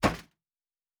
Metal Box Impact 1-5.wav